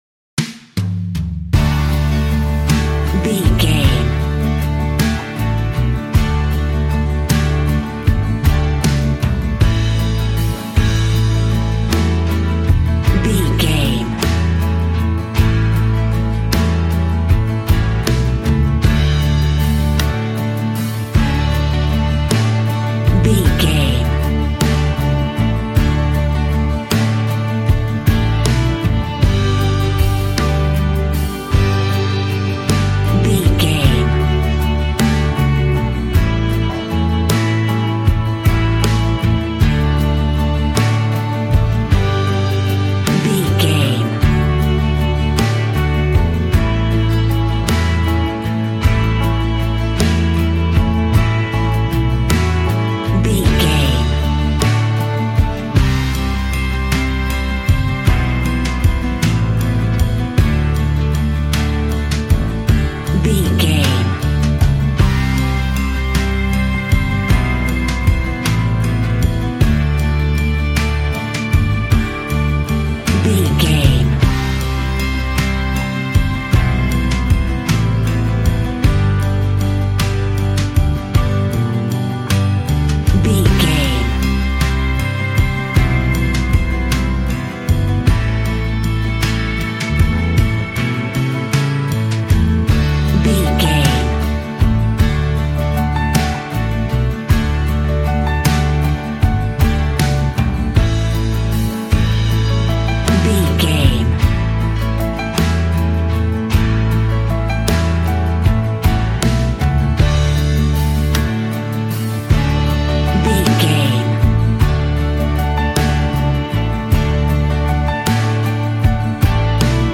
Ionian/Major
D
cheerful/happy
double bass
drums
piano